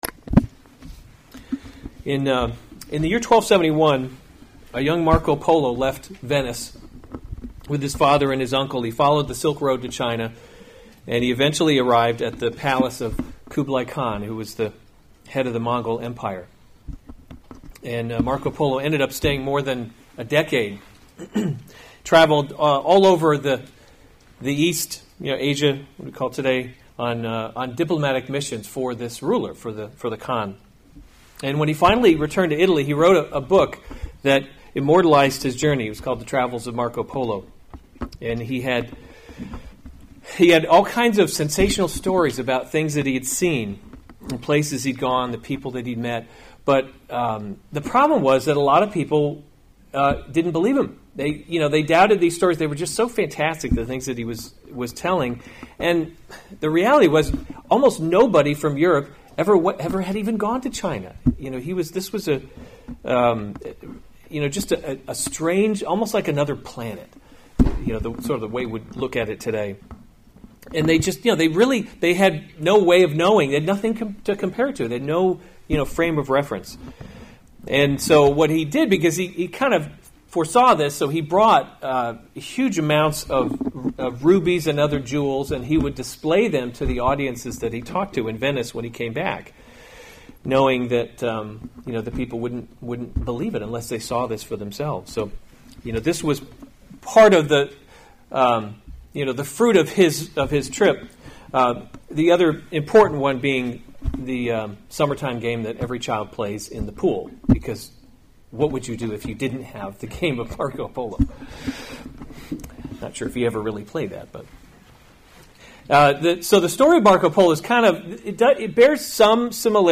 February 2, 2019 1 Kings – Leadership in a Broken World series Weekly Sunday Service Save/Download this sermon 1 Kings 10 Other sermons from 1 Kings The Queen of Sheba […]